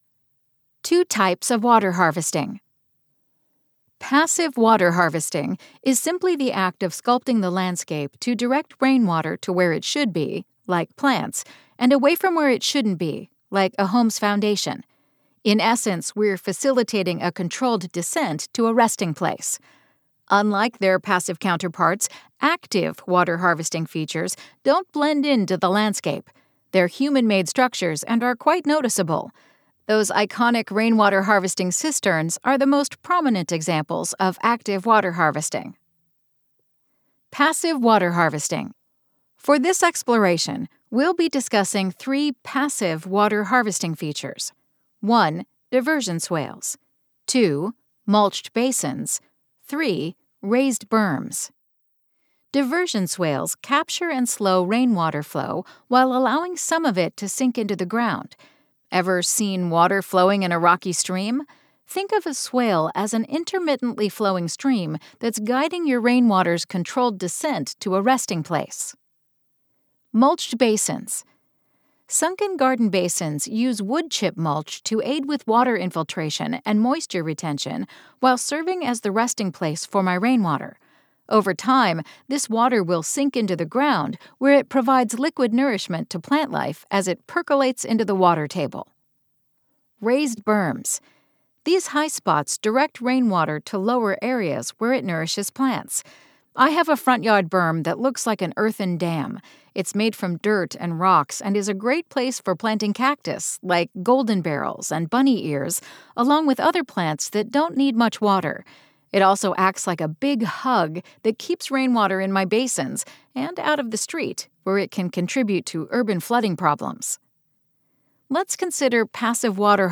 • Audiobook • 00 hrs 35 min